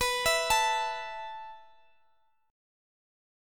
B7sus4 Chord
Listen to B7sus4 strummed